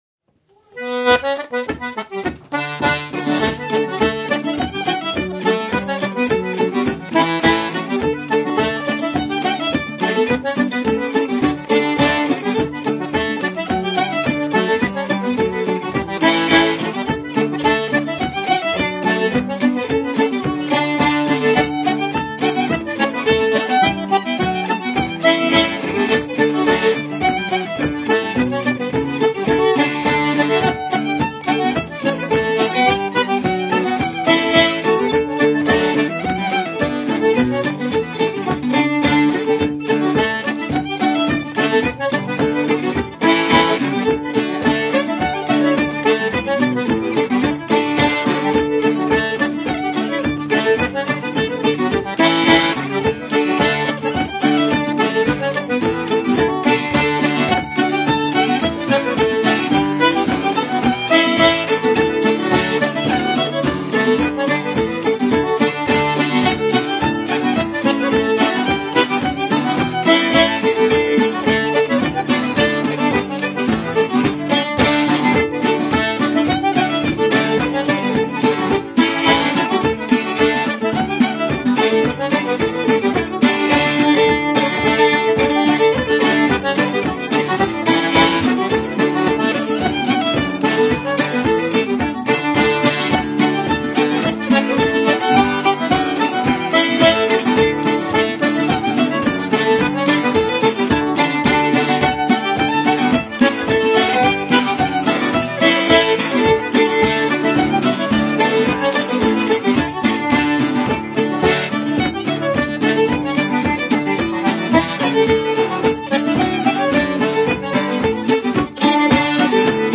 Below are links to mp3s of the FSC Country Dancing music as heard and used on camp.